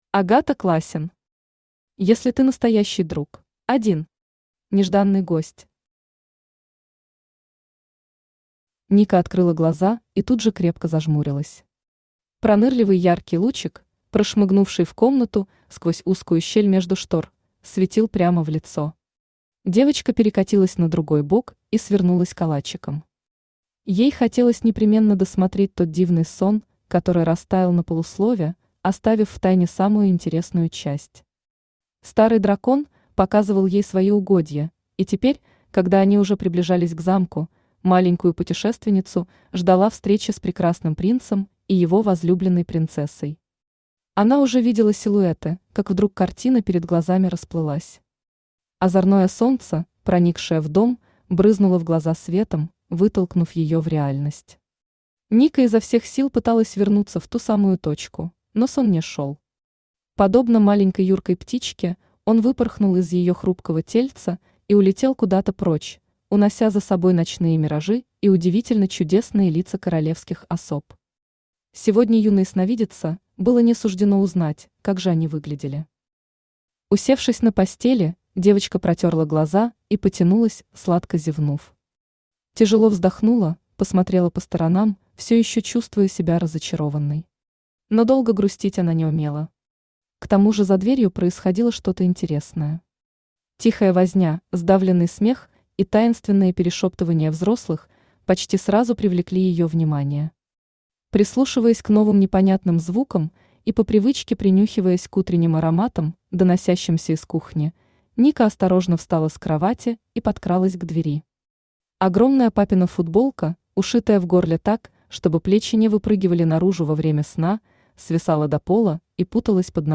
Аудиокнига Если ты настоящий друг…
Aудиокнига Если ты настоящий друг… Автор Агата Классен Читает аудиокнигу Авточтец ЛитРес.